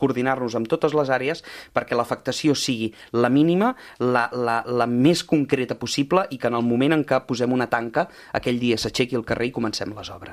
Ho ha dit el portaveu de Junts a l’Ajuntament de Calella i segon tinent d’Alcaldia de Fires, Activitat cultural i Serveis municipals, Josep Grima, a l’entrevista política de RCT.